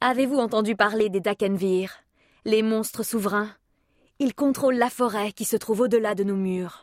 Jeune Femme (Jeu vidéo)